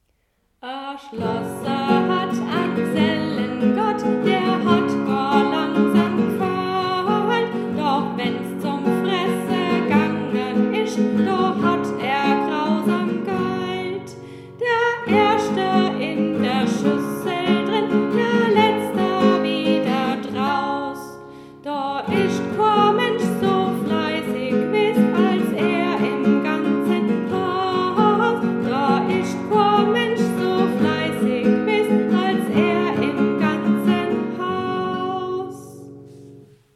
Dieses Lied wurde eingesungen und lässt sich für einen ersten Eindruck abspielen.